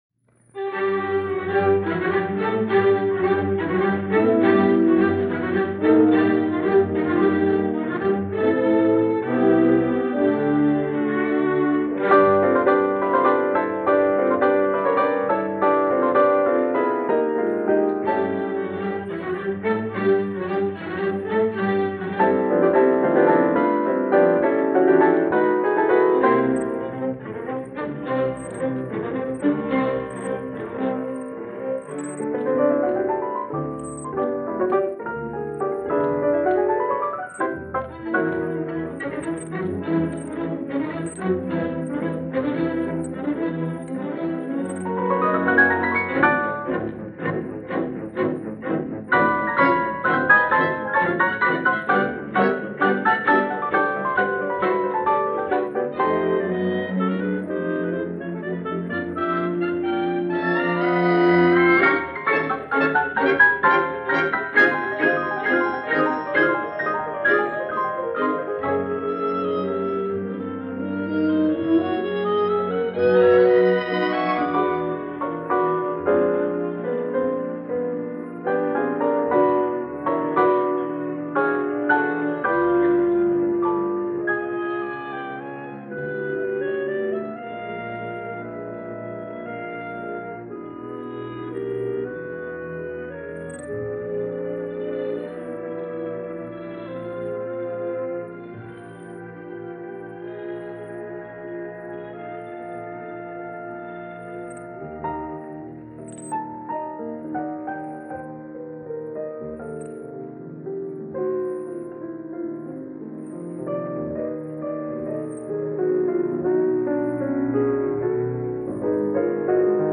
style closely resembled the Romantic period of music